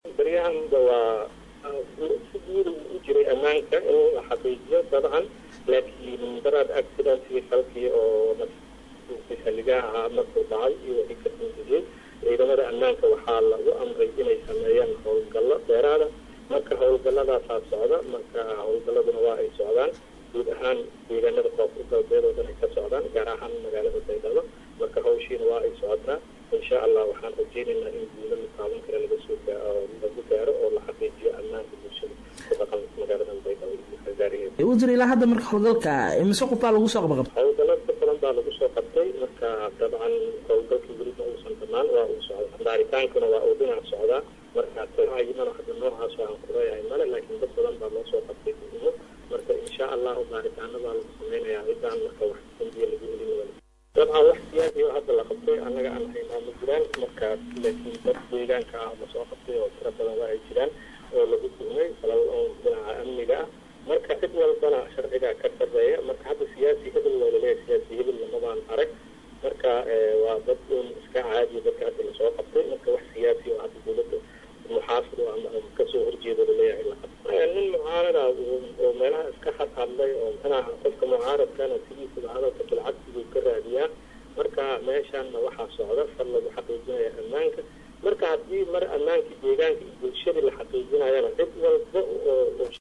Shariif Xasan ayaa xabsiga dhigey siyaasiyiin iyo dad argagixisada hoos ka taageera. Wasiir ku-xigeenka Wasaaradda Amniga Maamulka Koonfur Galbeed C/risaaq Cabdi Aadan oo la hadlay Warbaahinta ayaa sheegay in ujeedka laga leeyahay howlgalka uu yahay mid lagu xaqiijinayo amaanka ka dib weerarkii ismiidaaminta ah ee ka dhacay magaalada Baydhabo